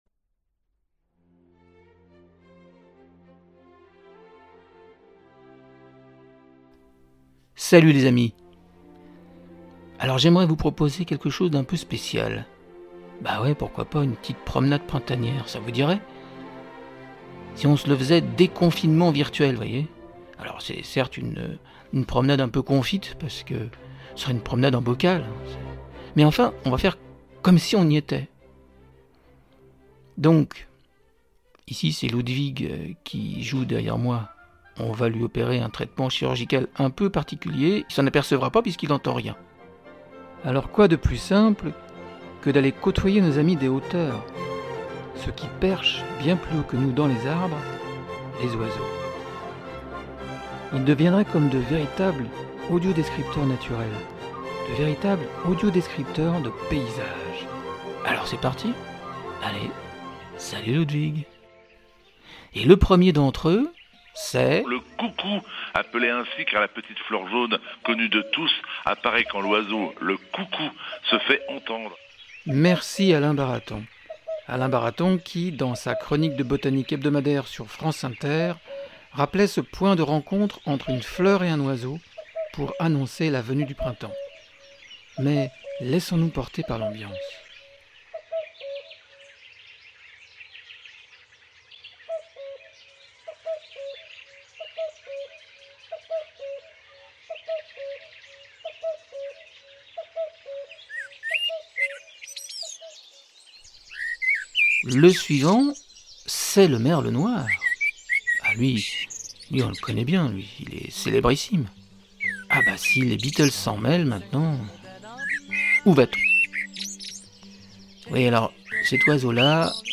Rencontrer quelques-uns de ces oiseaux qui enchantent nos oreilles...Écoutons-les...